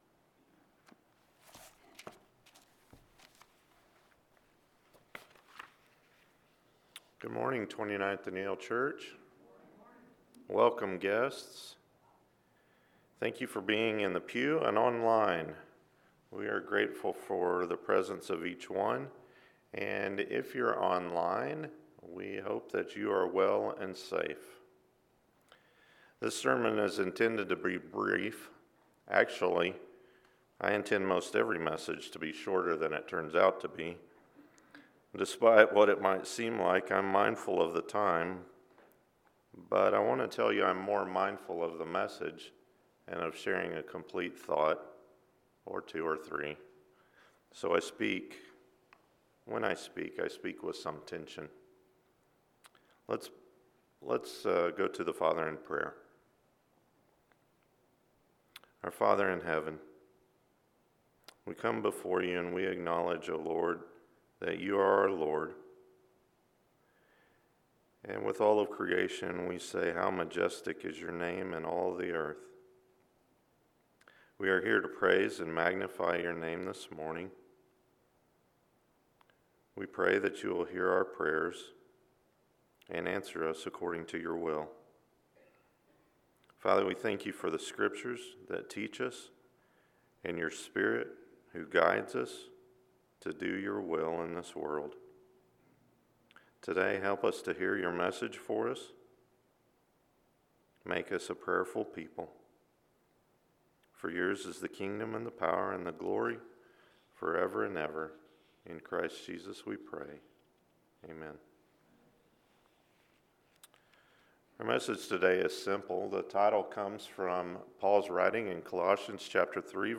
In Jesus’ Name – Colossians 3:17 – Sermon — Midtown Church of Christ